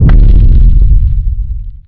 giant_hit.ogg